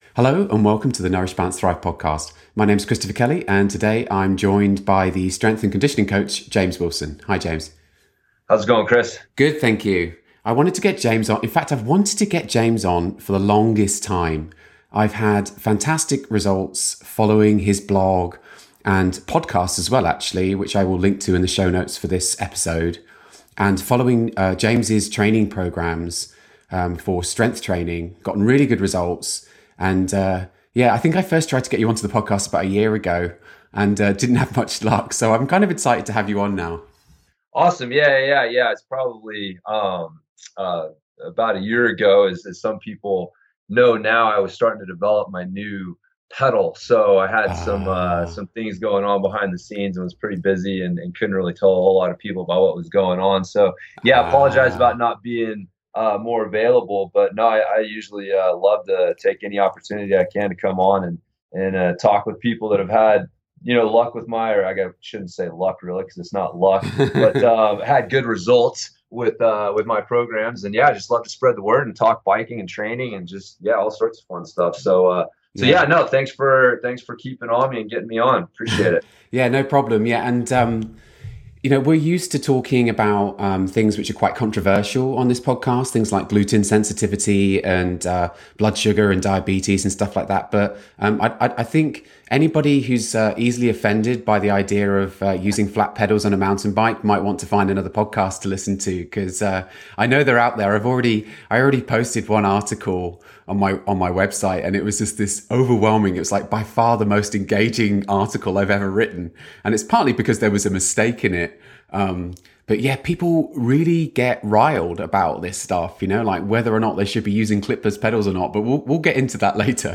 As you’ll hear in this podcast, and as has been shown in the studies cited below, there is no advantage in pulling up on the backstroke, and so this is not a good reason to be using clipless pedals. Not long after we recorded this interview, my new Catalyst pedals arrived and since then I've been enjoying wiggling my toes as I pedal comfortably in a midfoot position.